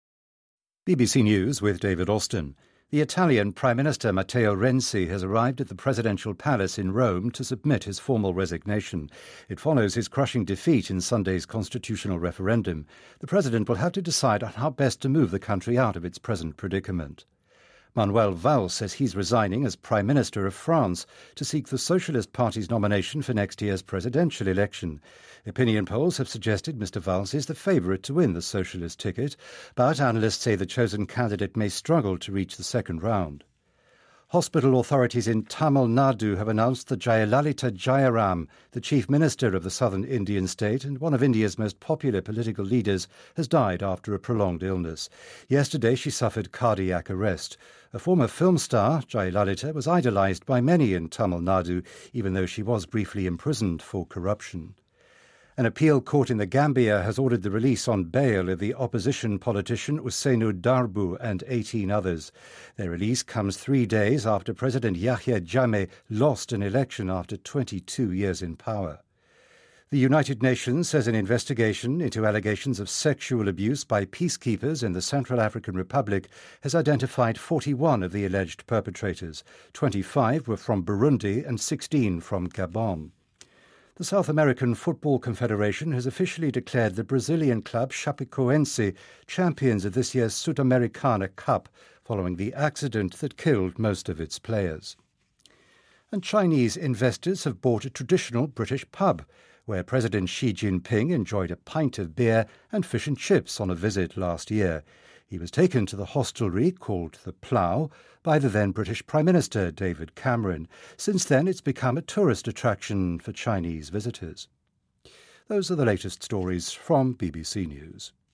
日期:2016-12-07来源:BBC新闻听力 编辑:给力英语BBC频道